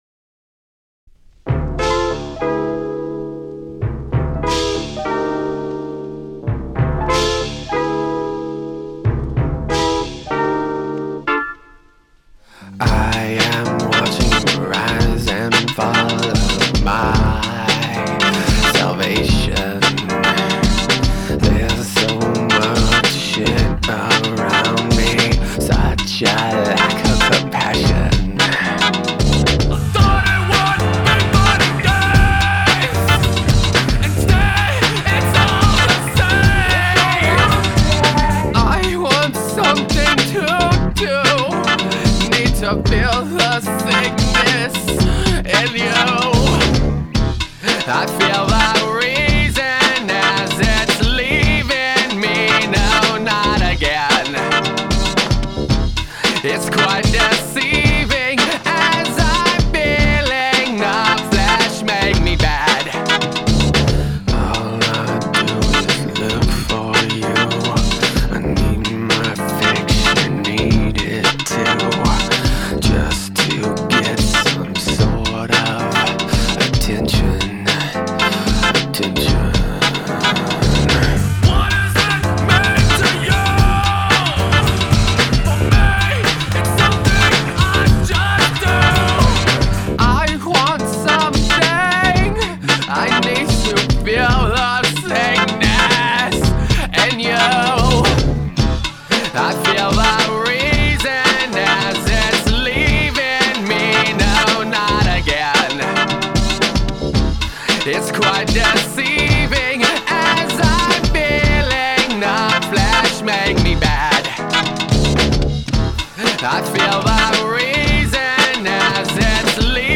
all mashups